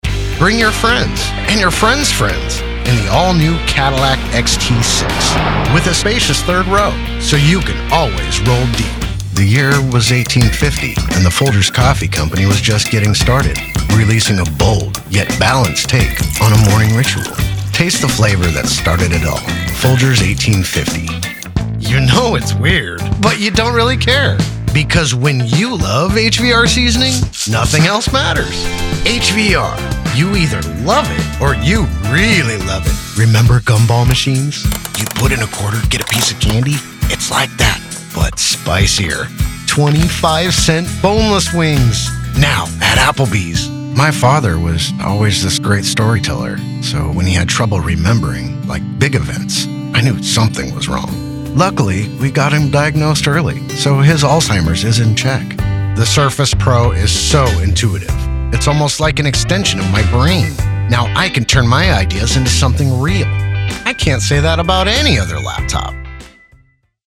Commercial Demo
Middle Aged
I have a broadcast quality home studio and love connecting for directed sessions.